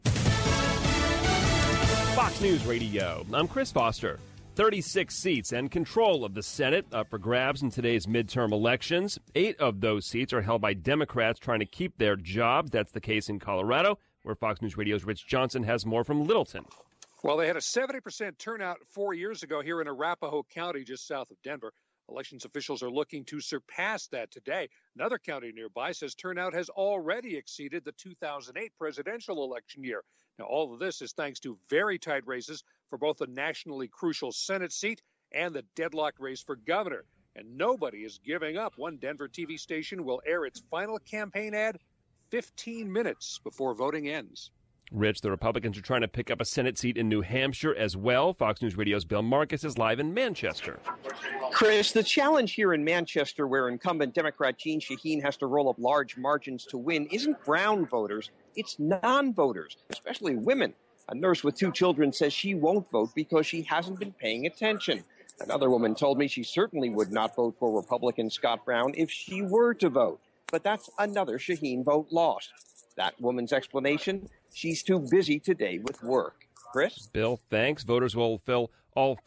recording a spot in Panera Bread, Manchester New Hampshire at 1pm